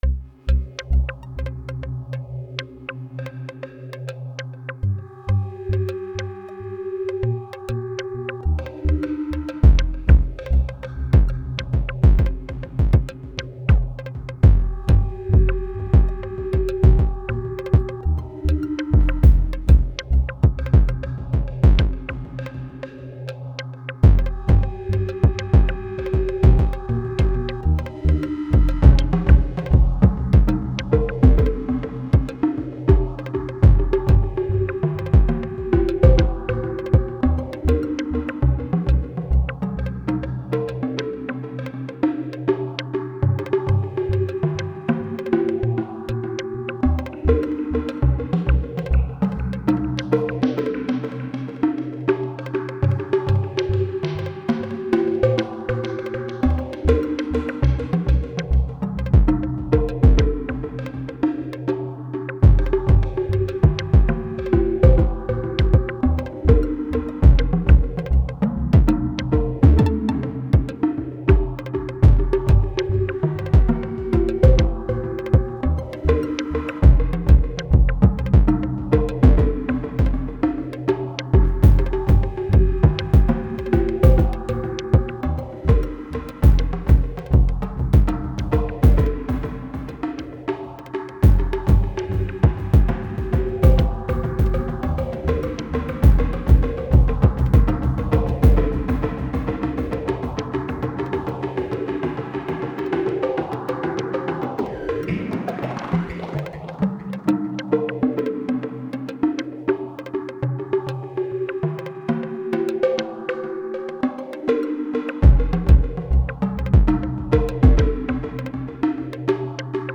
Variation of the same sounds, single pattern and 6-7 tracks of TOY. It may sound as fewer, as I’m doubling and panning tracks for more dense sound. With a slight detune against each other, it makes doubled tracks sound more substantial.